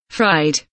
fried kelimesinin anlamı, resimli anlatımı ve sesli okunuşu